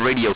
p_radio.wav